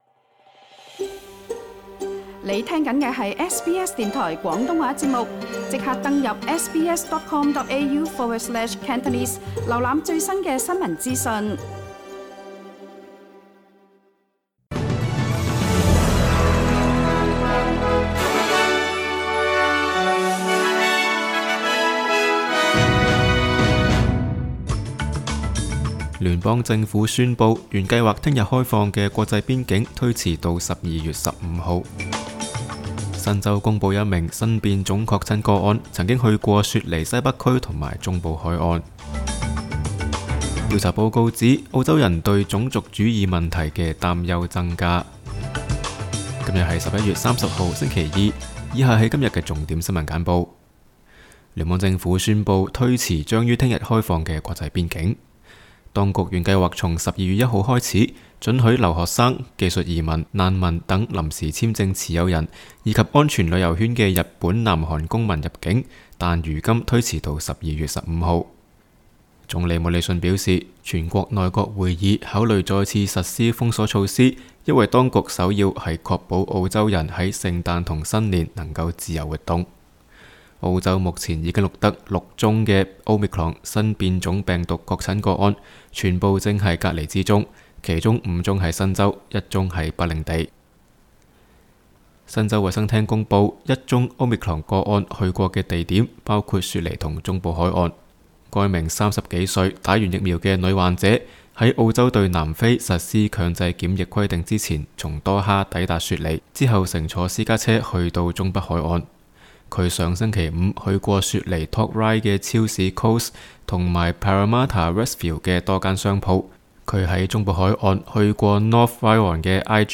SBS 新聞簡報（11月30日）
SBS 廣東話節目新聞簡報 Source: SBS Cantonese